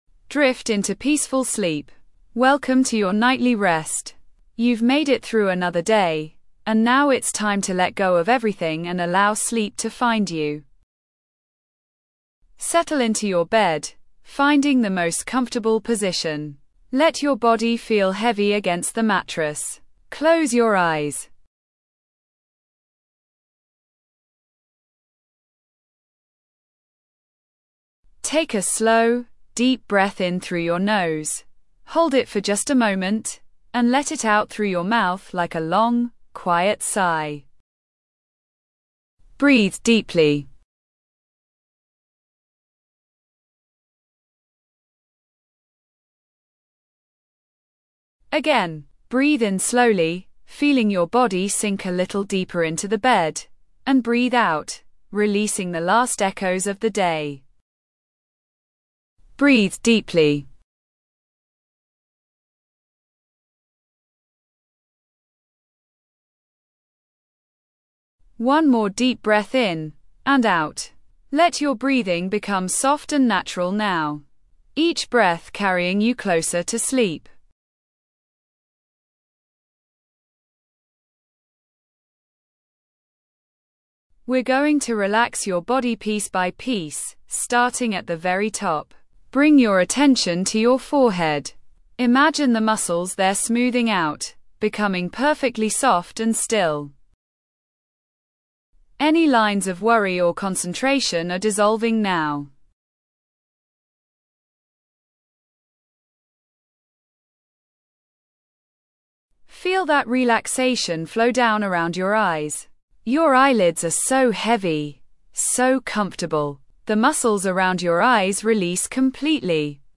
sleep 20 min beginner
Drift into Peaceful Sleep: A Bedtime Meditation
A calming bedtime meditation designed to quiet your mind and ease you into deep, restorative sleep.
drift-into-peaceful-sleep.mp3